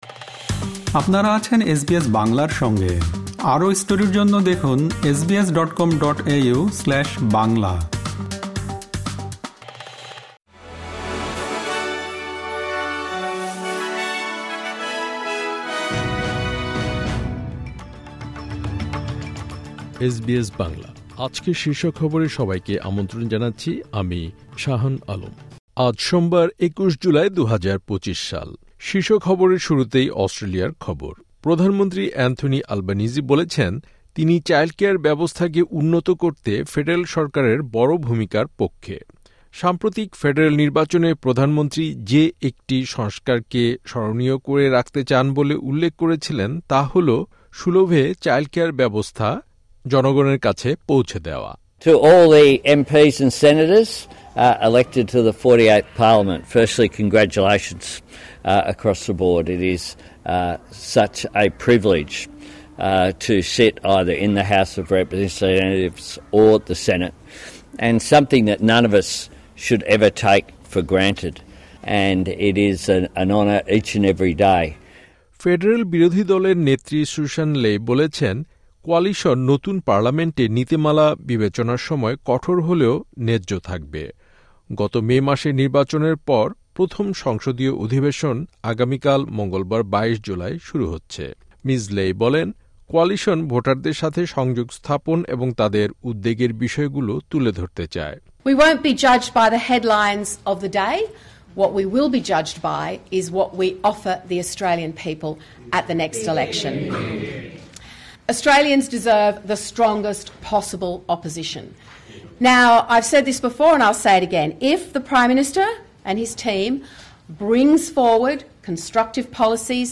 এসবিএস বাংলা শীর্ষ খবর: ২১ জুলাই, ২০২৫